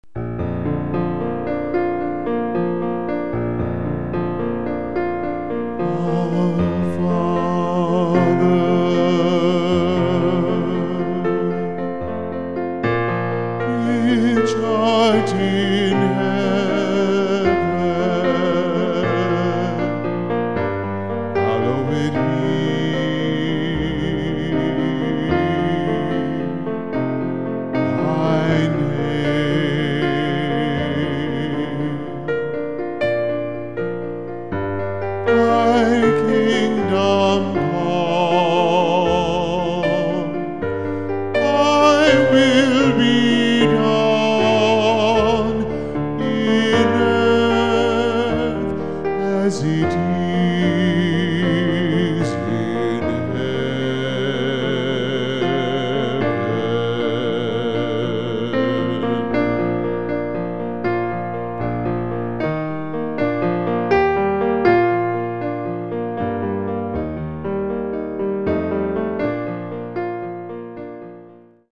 solo piano/vocal